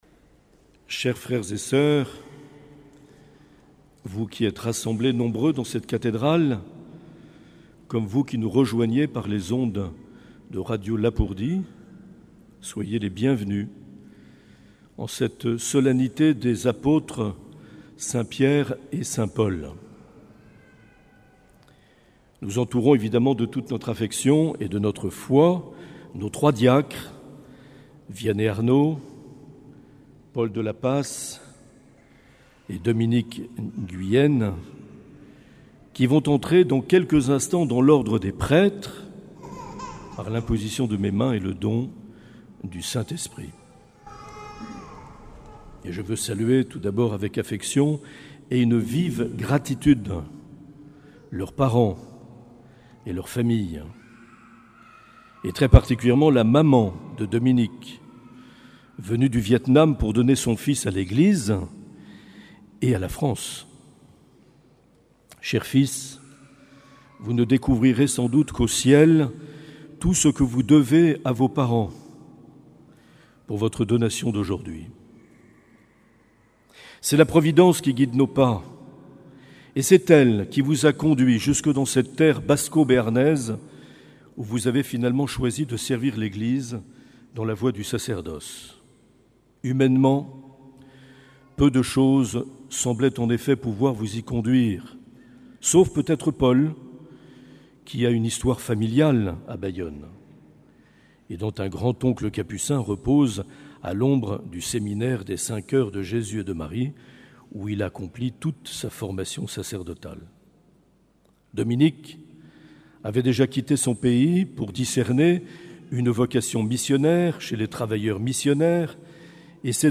30 juin 2019 - Cathédrale de Bayonne - Ordinations presbytérales.